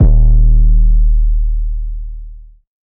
808 (OuttaSpace).wav